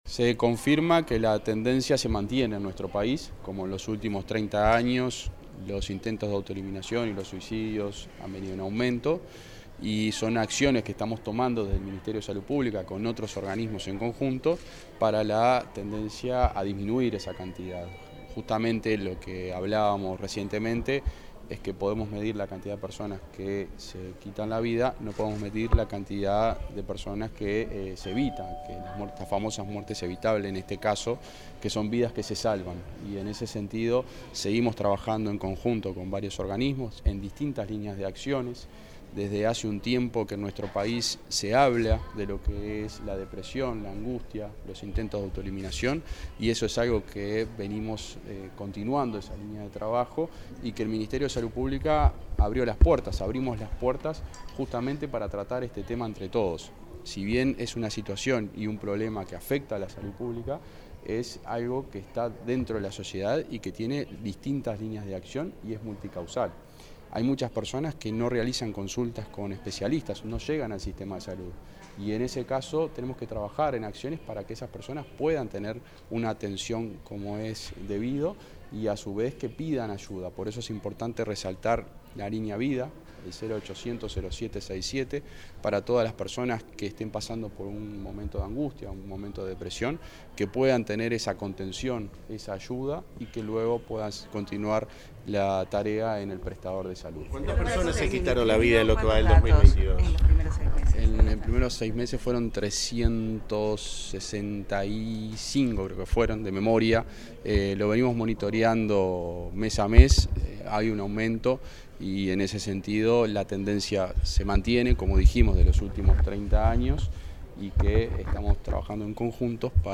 Declaraciones del ministro interino de Salud Pública a la prensa